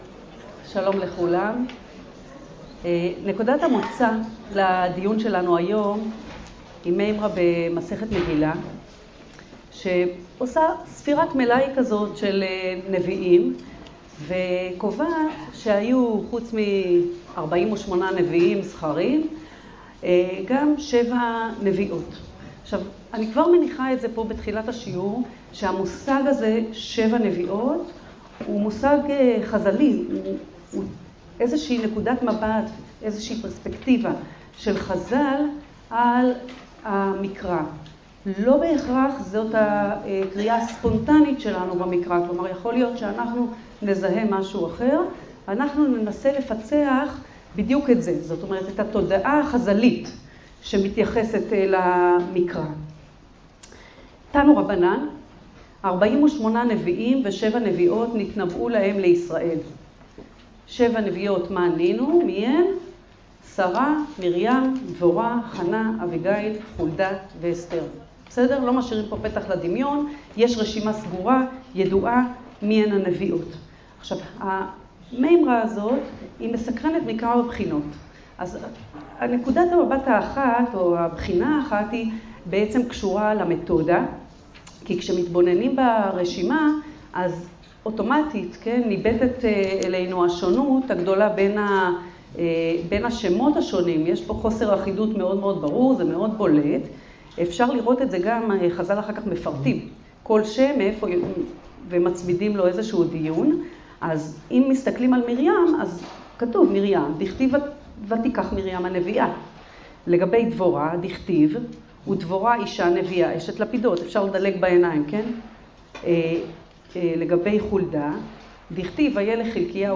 השיעור באדיבות אתר התנ"ך וניתן במסגרת ימי העיון בתנ"ך של המכללה האקדמית הרצוג